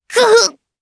Nia-Vox_Damage_jp_01.wav